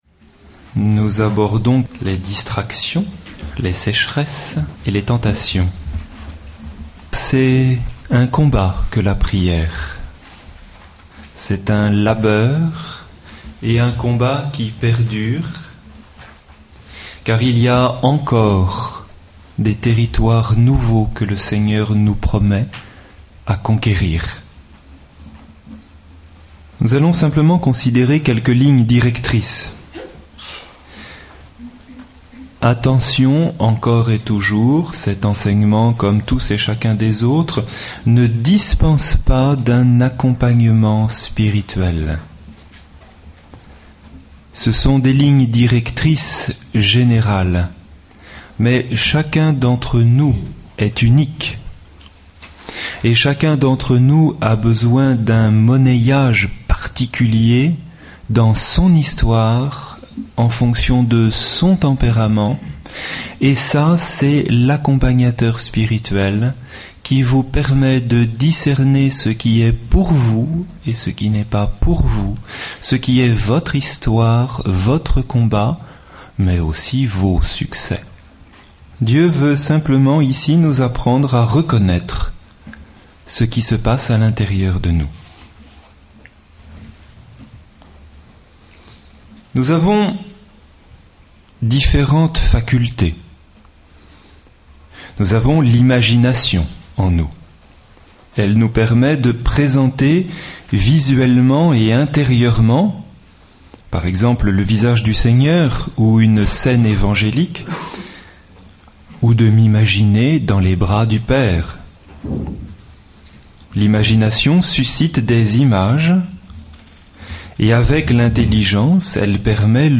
Enseignement